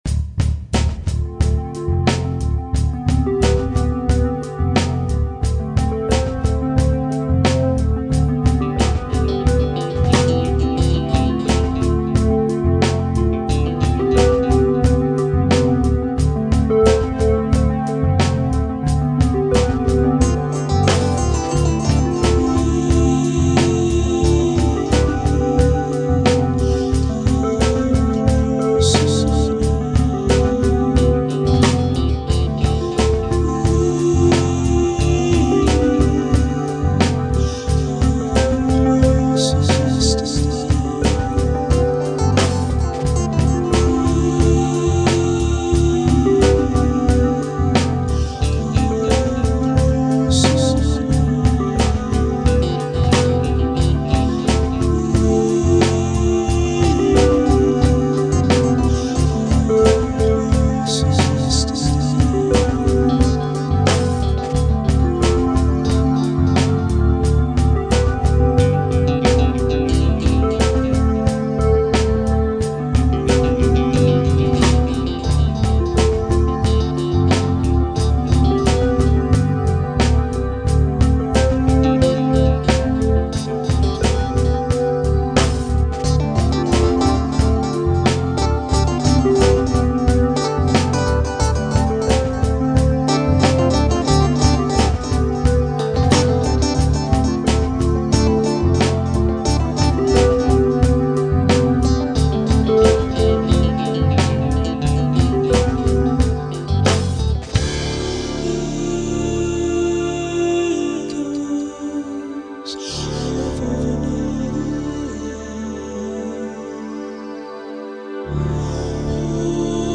This is a modern instrumentation making heavy use of synthesiser effects, drawing on the themes of musical repetition mirroring the uncontrollable repetitions in life, of behaviour of instinct of love of relationships and of sexual habits.